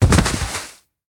0015_脸和地面摩擦.ogg